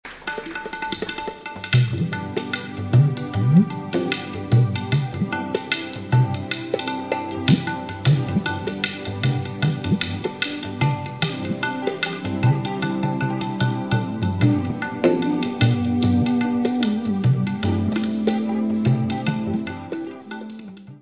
guitarra, caja
voz e lávium
guitarra, percussão e voz
tabla e voz.
Music Category/Genre:  World and Traditional Music